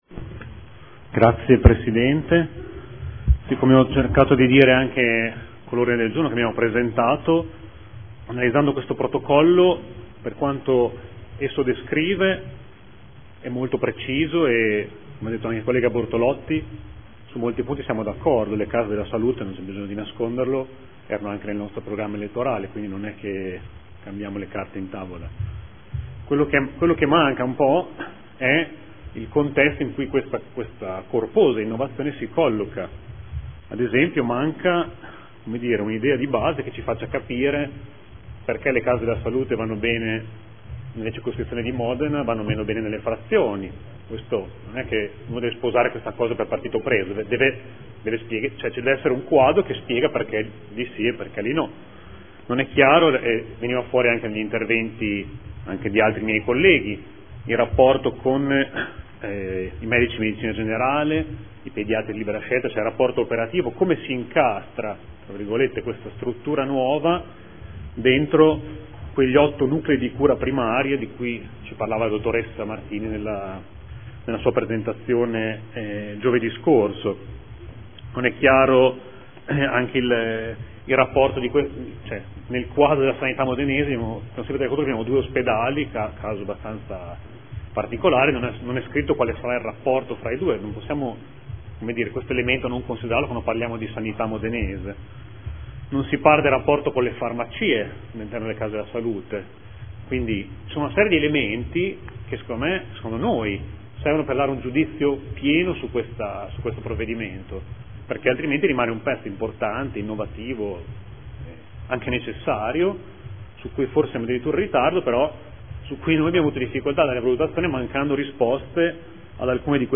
Seduta del 3/11/2014. Dibattito su ordini del giorno
Audio Consiglio Comunale